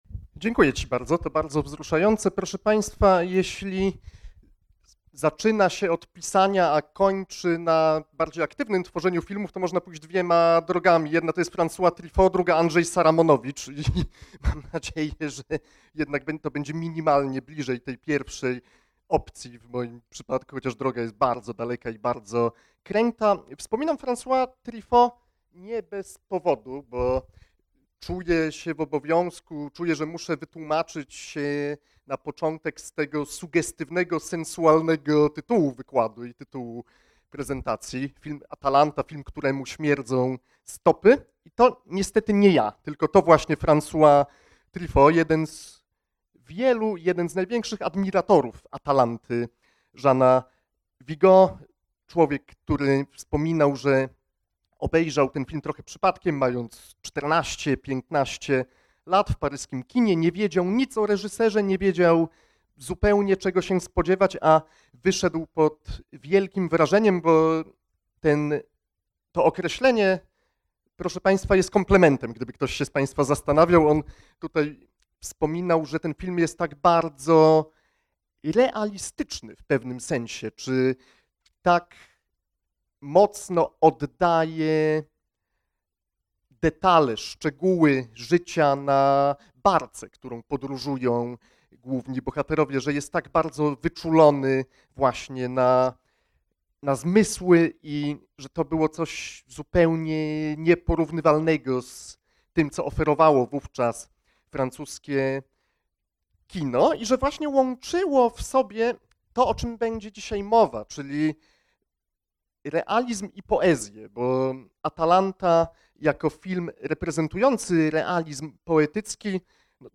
Wykład z 11 marca 2025